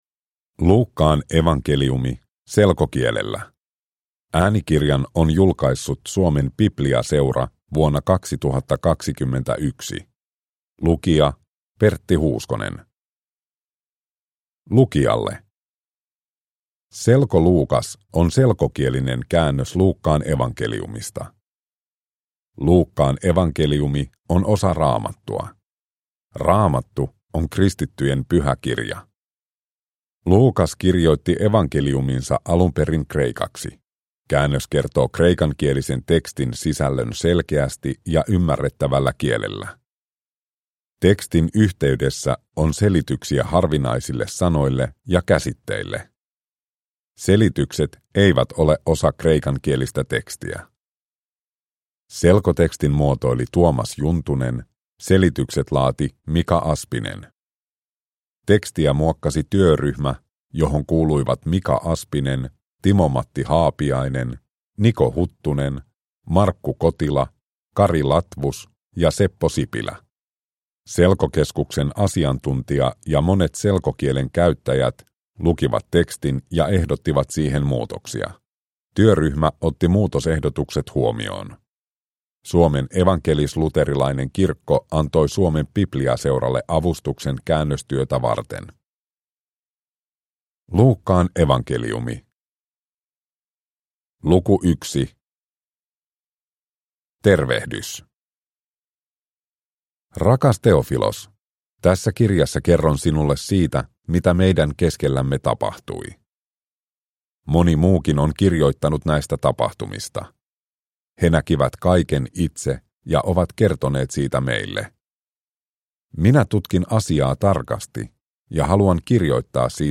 Luukkaan evankeliumi selkokielellä – Ljudbok – Laddas ner